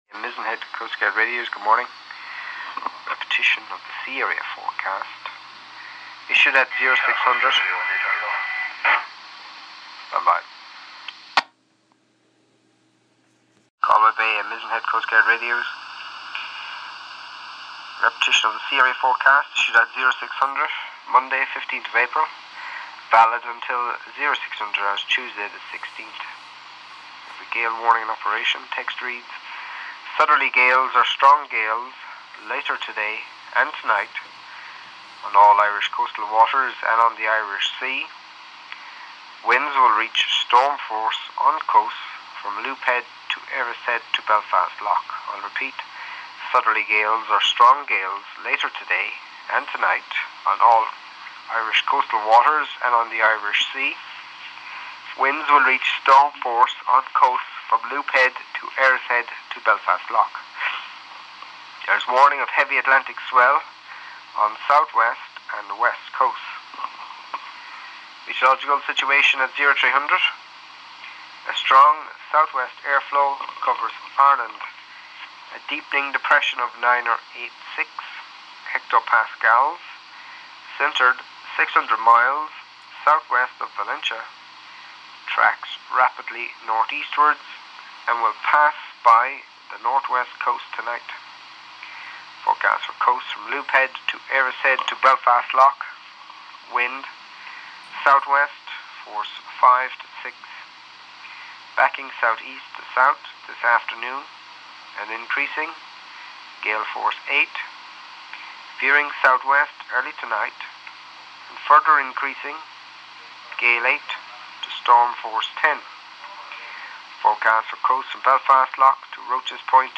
Sea area forecast by Valentia coastguard